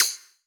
normal-slidertick.wav